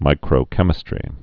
(mīkrō-kĕmĭ-strē)